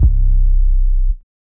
nw808 2.wav